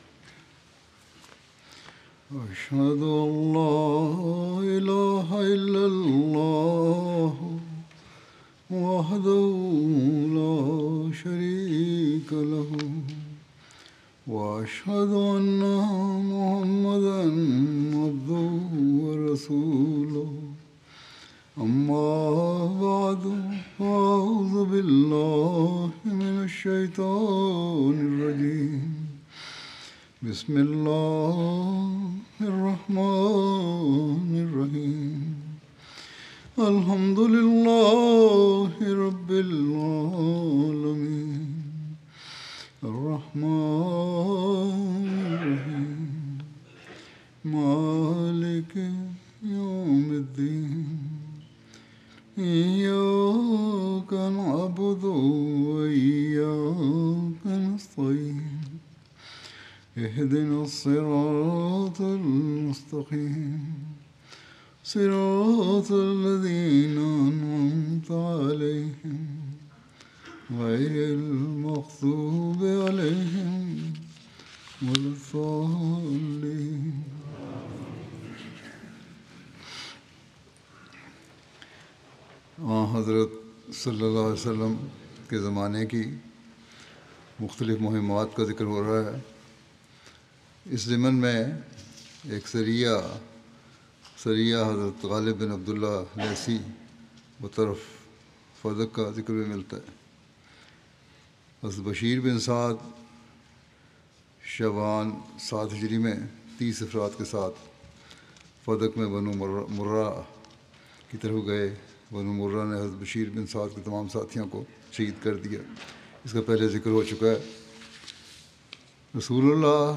Urdu Friday Sermon by Head of Ahmadiyya Muslim Community
Urdu Friday Sermon delivered by Khalifatul Masih